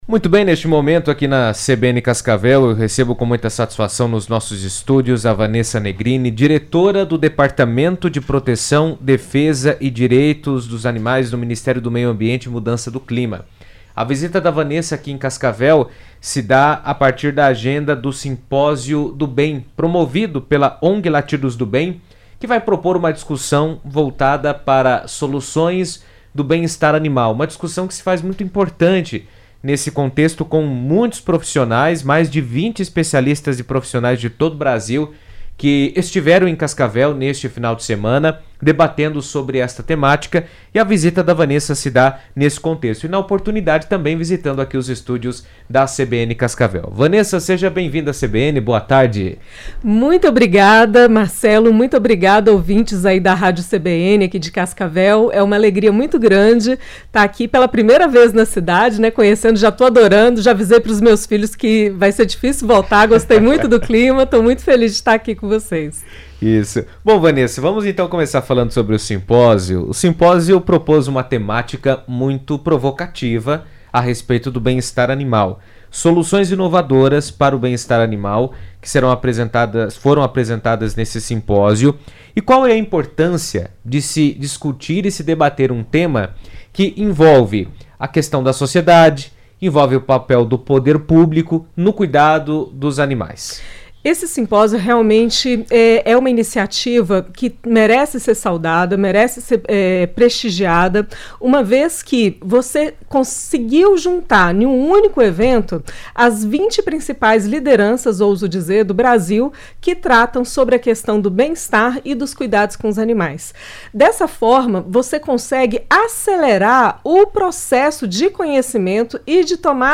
O bem-estar animal é debatido a partir do papel da sociedade, das ONGs e do poder público na proteção dos animais. O tema foi abordado no Simpósio do Bem, que promoveu reflexões sobre políticas de proteção animal, e Vanessa Negrini, diretora do Departamento de Proteção, Defesa e Direitos dos Animais do Ministério do Meio Ambiente e Mudança do Clima, esteve na CBN comentando o assunto.